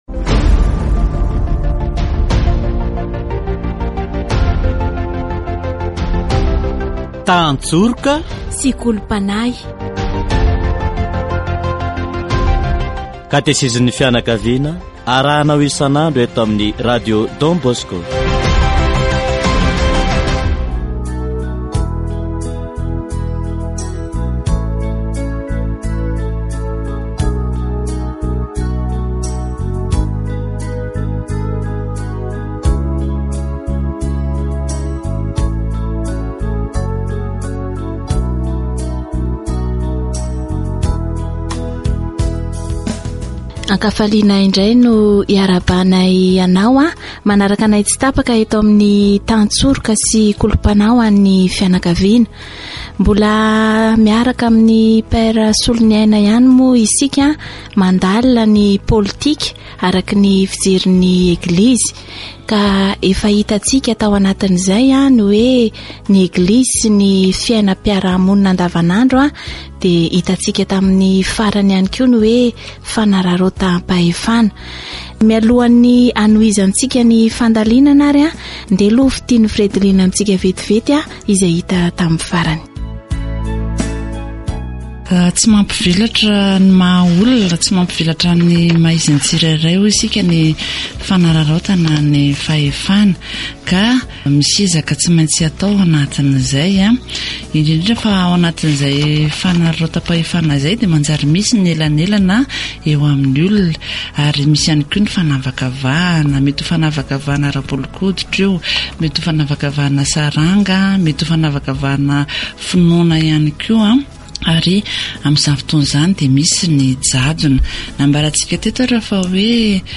Katesizy momba ny politika araka ny fijerin'ny Eglizy